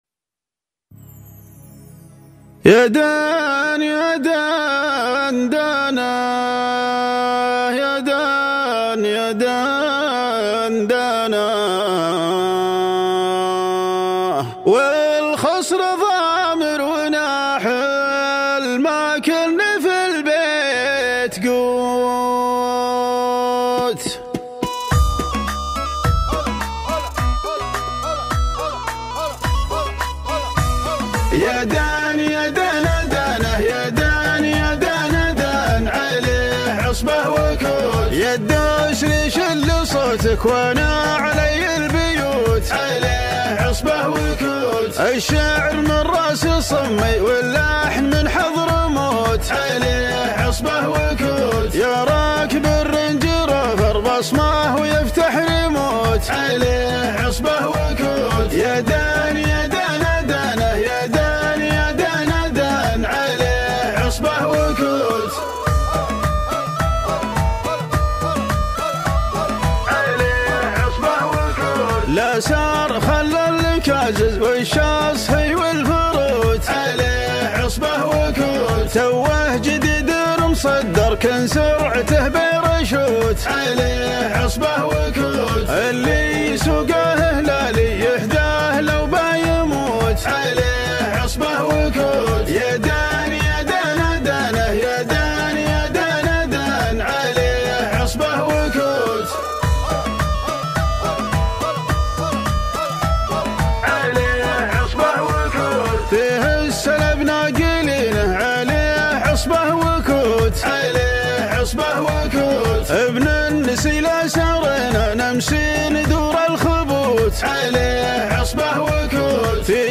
شيلات طرب